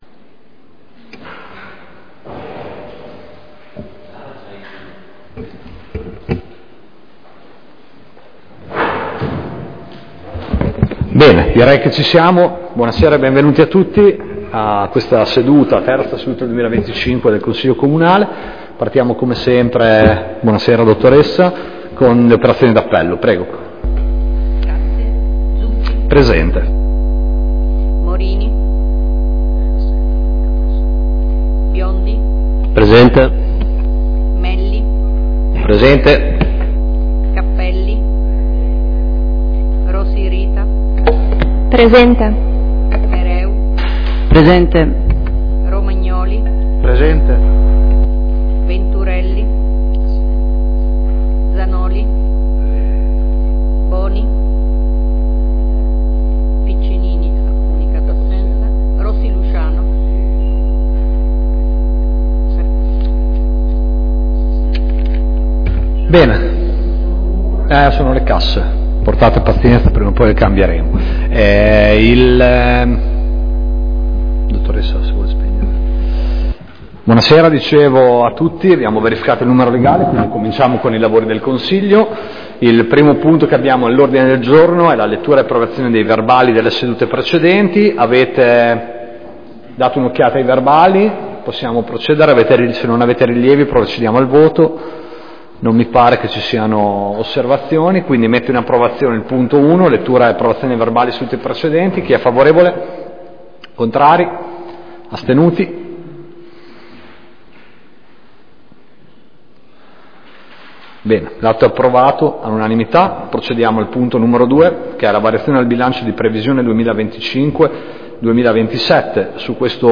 Consiglio Comunale del 26/03/2025
Scarica la registrazione audio della seduta ( - 36,8 MB)